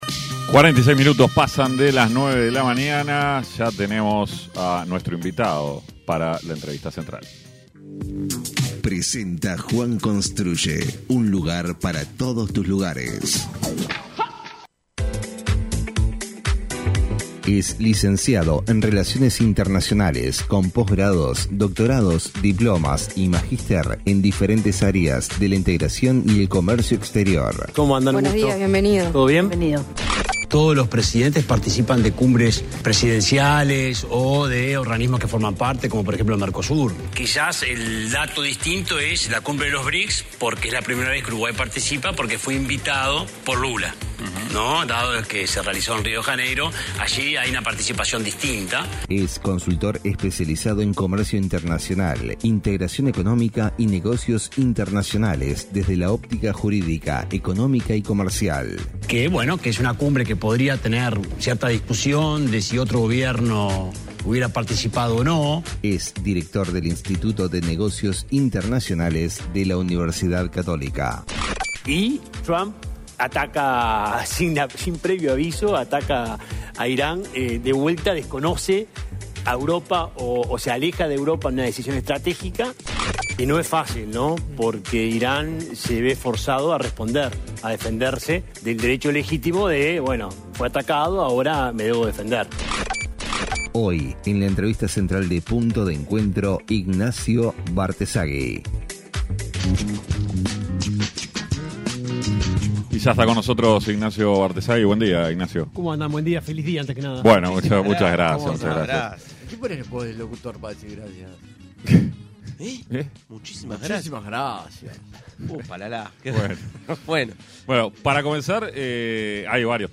En entrevista con Punto de Encuentro